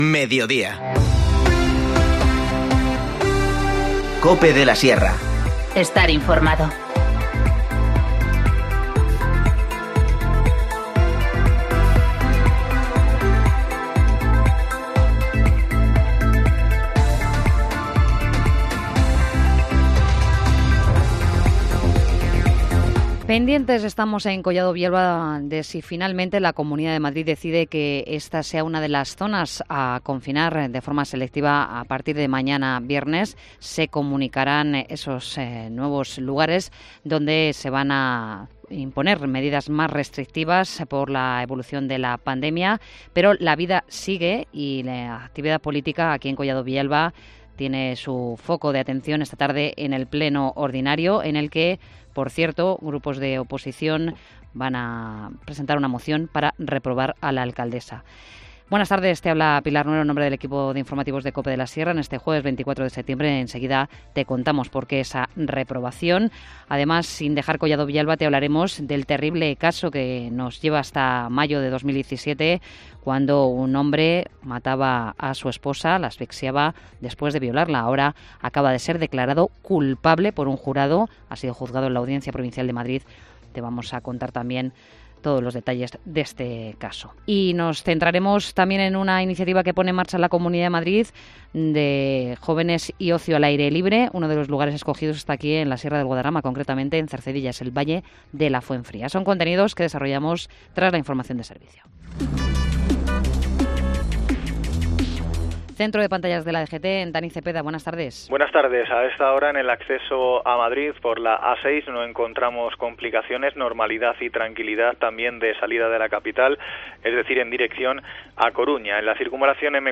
Informativo Mediodía 24 septiembre